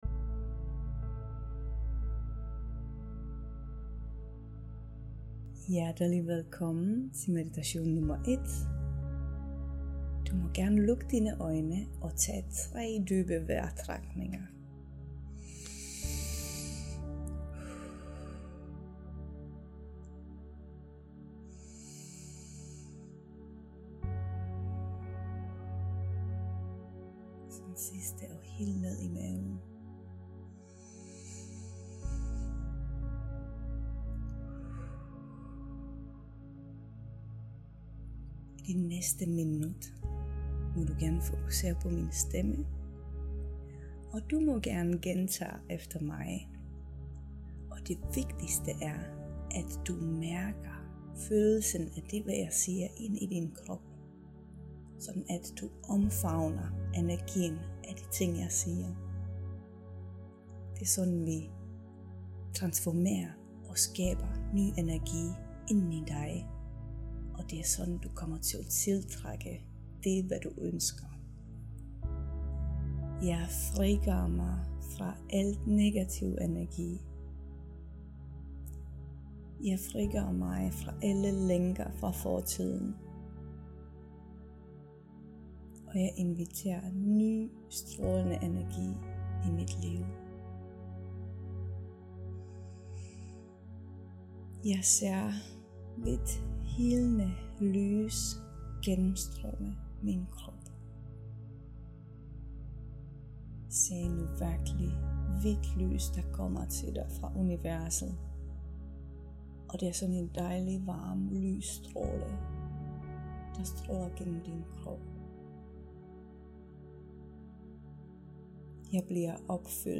Lav gerne en energi tjek inden du hører til den første meditation.